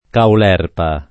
[ kaul $ rpa ]